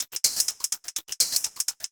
RI_RhythNoise_125-02.wav